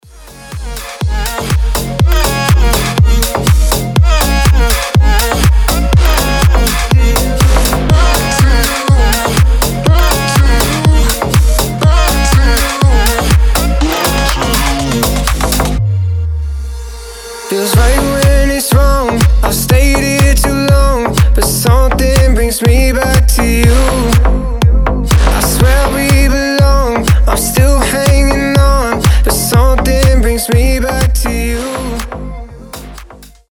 • Качество: 320, Stereo
громкие
deep house
красивый мужской голос
EDM
Club House
Brazilian bass